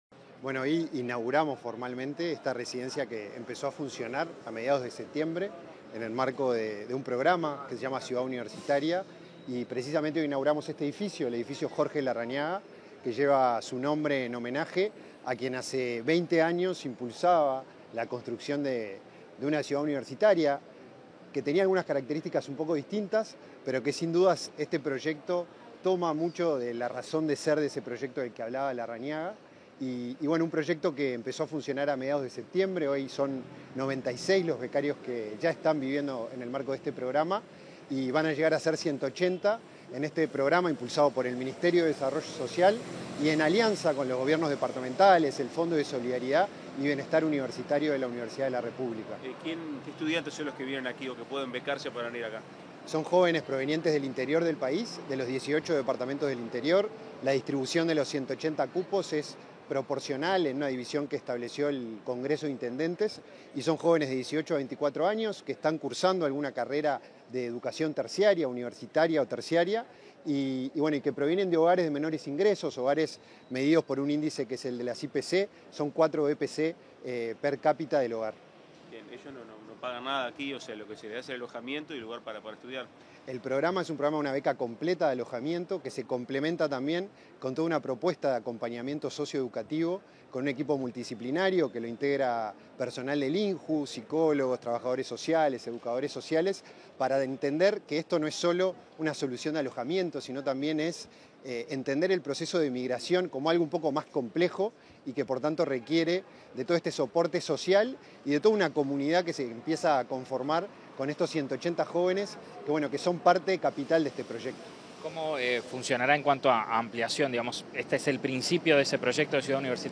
Declaraciones a la prensa del presidente del INJU, Felipe Paullier
Con motivo de la inauguración de la Ciudad Universitaria Jorge Larrañaga, este 24 de octubre, el presidente del Instituto Nacional de la Juventud